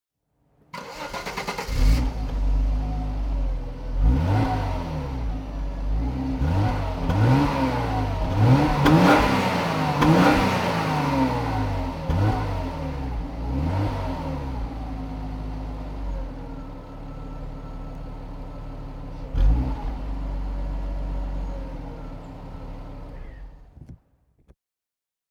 Jaguar XJ6 3.2 Executive (1997) - Starten und Leerlauf